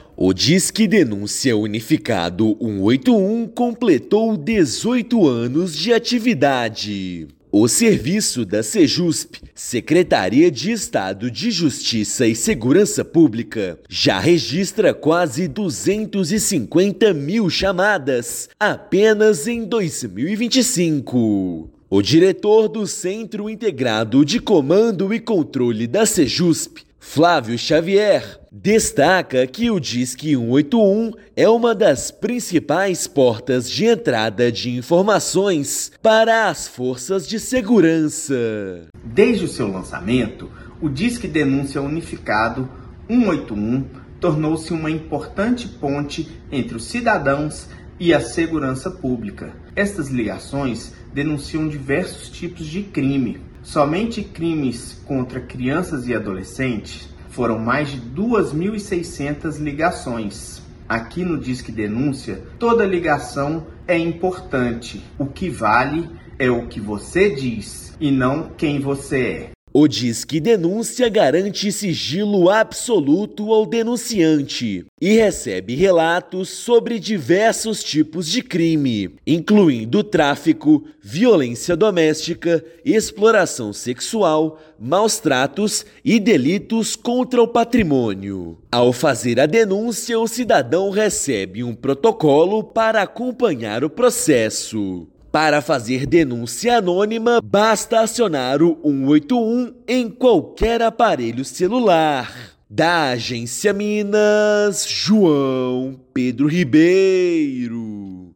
Disque Denúncia, que completa 18 anos neste sábado (29/11), fortalece investigações e possibilita desde capturas de foragidos à desarticulação de redes criminosas. Ouça matéria de rádio.